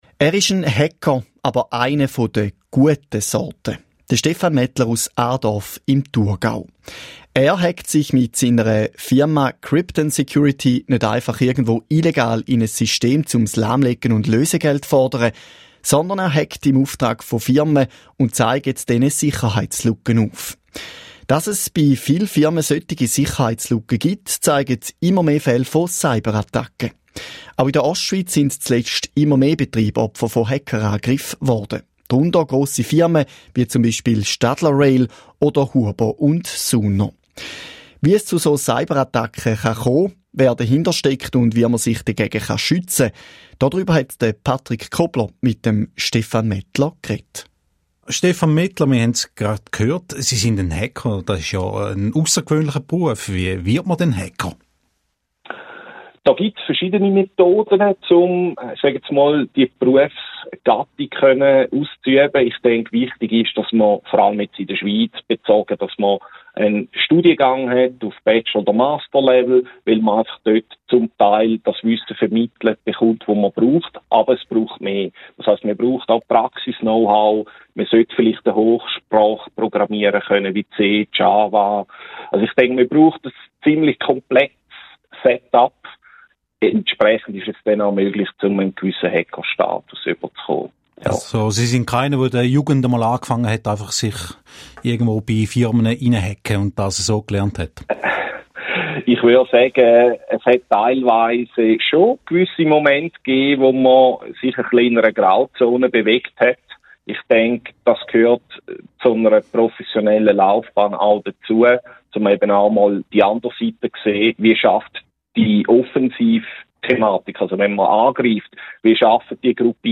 Interview mit dem SRF Regionaljournal Ostschweiz